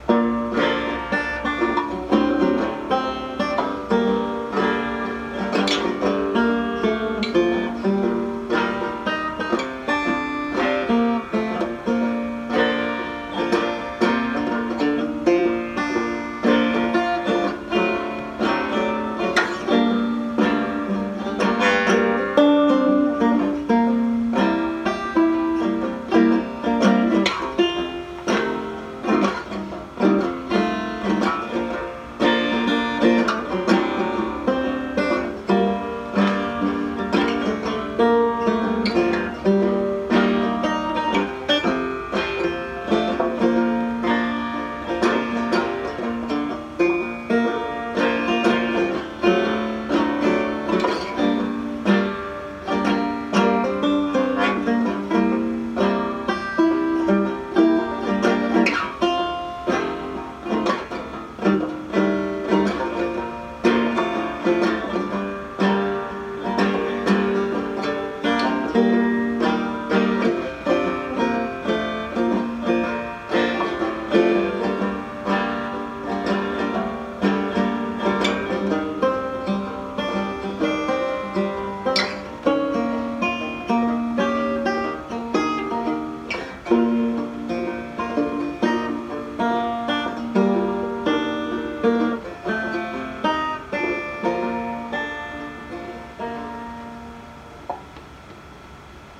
acoustic Acoustic clean guitar open-chords sound effect free sound royalty free Music